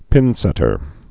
(pĭnsĕtər)